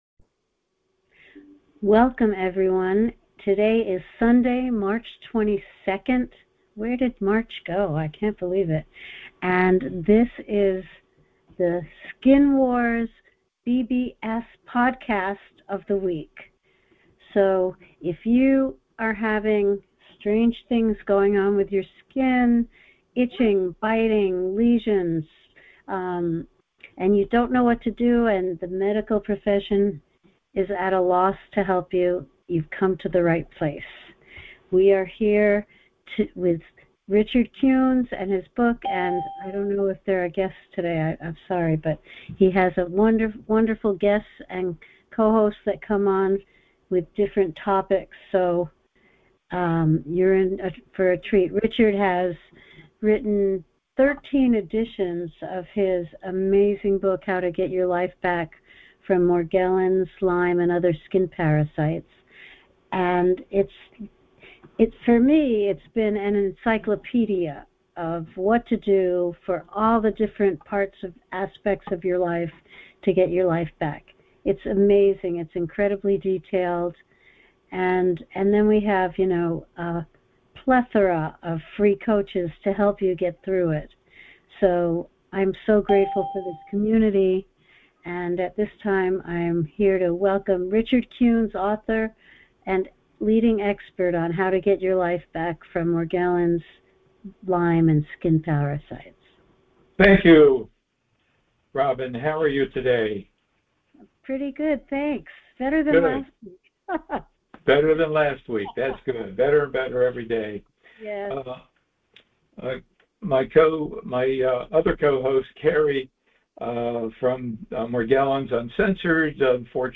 Talk Show Episode, Audio Podcast, Skin Wars and Co-infections, The Restrictive Dietary Protocol, Environmental Decontamination and Recovery from Morgellons on , show guests , about Defining Morgellons,Co-infections,Restrictive Dietary Protocol,Environmental Decontamination,Protection,Specialized Skin Care,Supplements,Reclaiming Life,Holistic, categorized as Education,Health & Lifestyle,Kids & Family,Medicine,Emotional Health and Freedom,Science,Self Help,Society and Culture